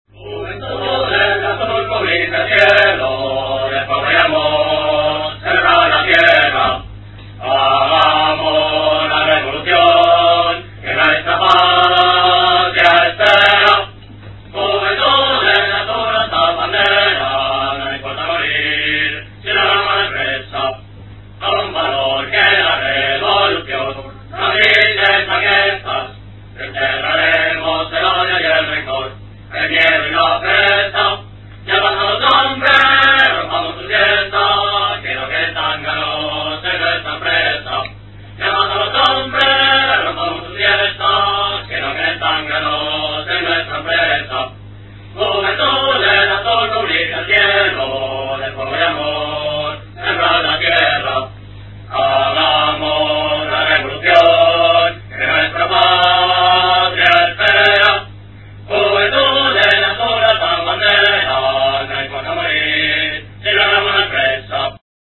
Himno del Grado de cadetes "Hispanidad" del Distrito de Chamart�n.